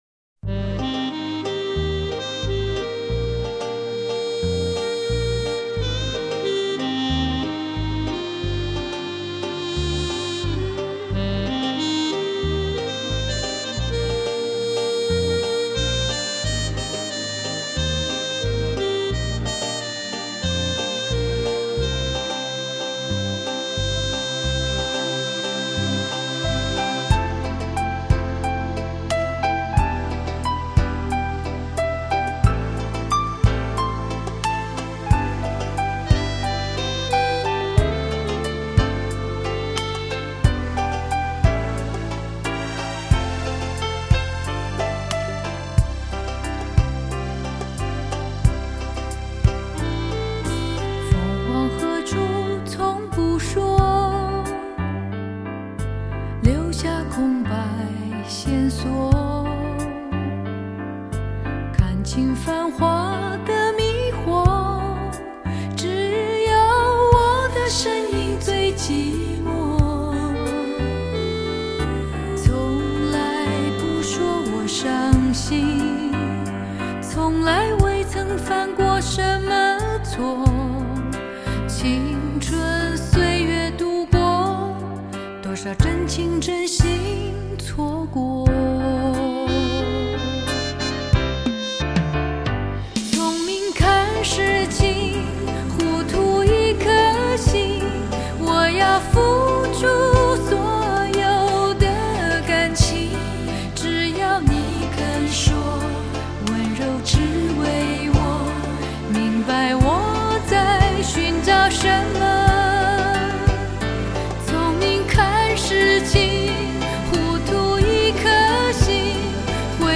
24K黄金版HDCD
专辑中歌曲以HDCD技术进行重新编码处理，音质表现比老版更佳。